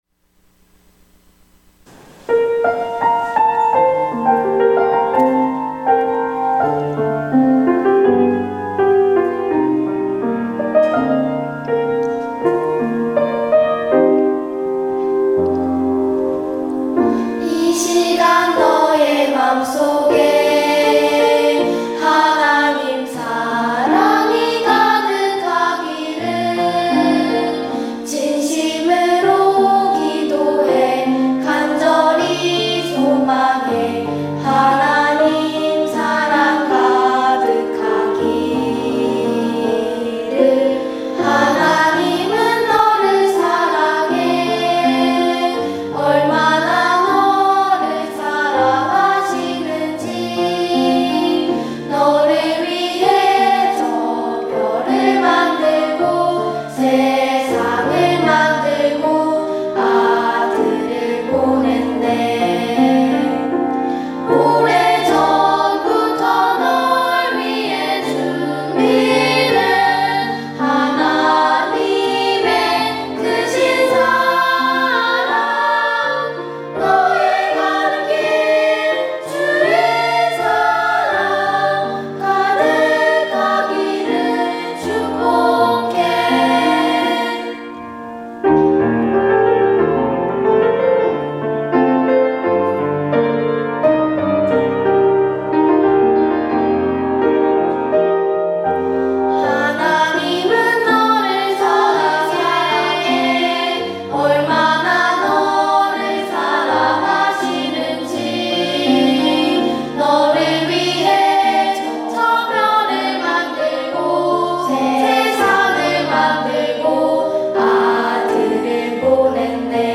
특송과 특주 - 이 시간 너의 맘 속에
유스콰이어 중창단